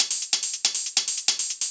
Index of /musicradar/ultimate-hihat-samples/140bpm
UHH_ElectroHatB_140-02.wav